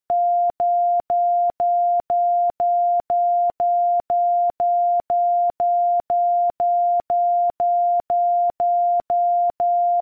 Two small marker beacons were also used: one 300 m off the end of runway, the HEZ (German: Haupteinflugzeichen, lit.'main approach signal'), and another 3 km away, the VEZ (German: Voreinflugzeichen, lit.'pre-approach signal'), both were broadcast on 38 MHz and modulated at 1700 and 700 Hz, respectively. These signals were broadcast directly upward, and would be heard briefly as the aircraft flew over them.